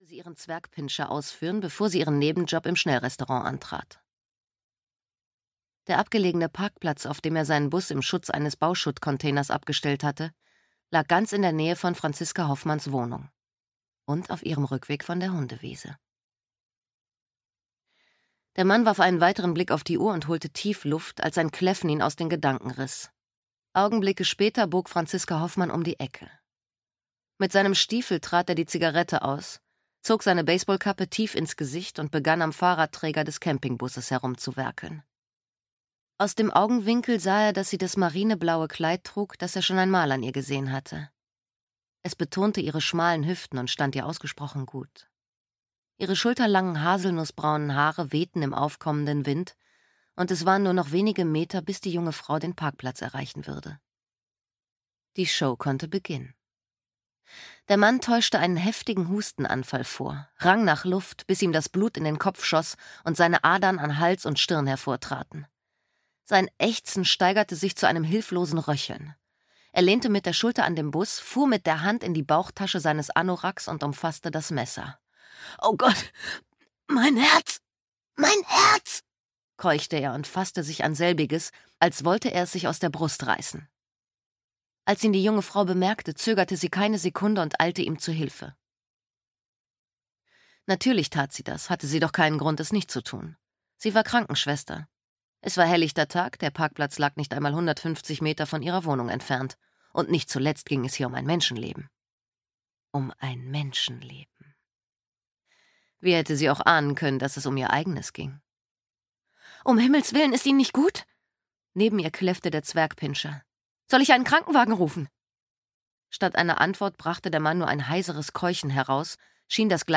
Stirb - Hanna Winter - Hörbuch